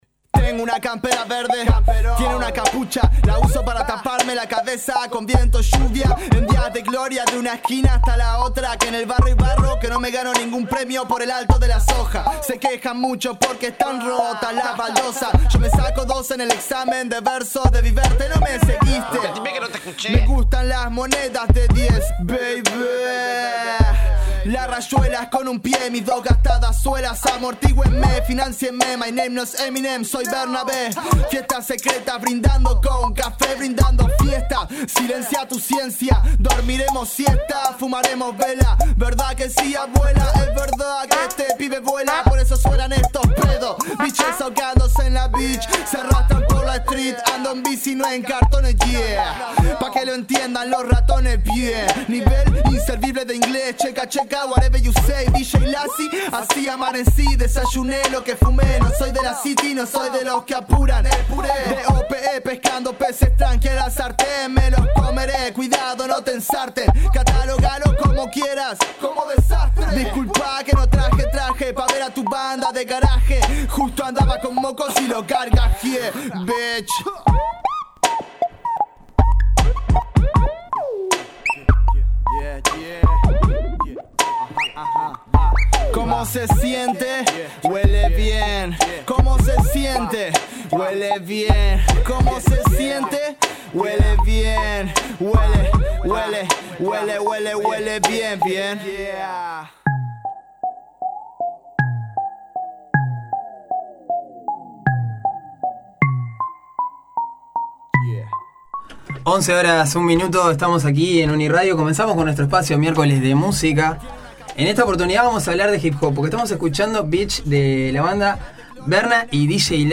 dúo de hip hop uruguayo